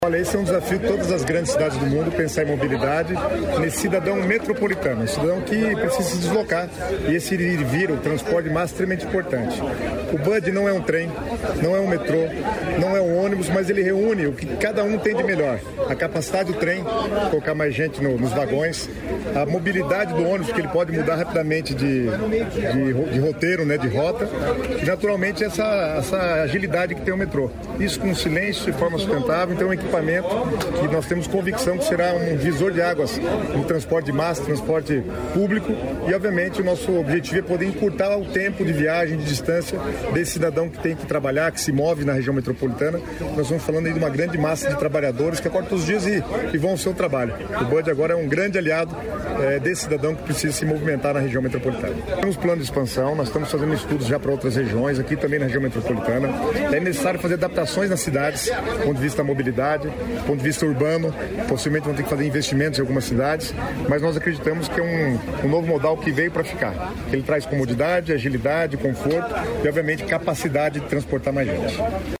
Sonora do secretário das Cidades, Guto Silva, sobre o Bonde Urbano Digital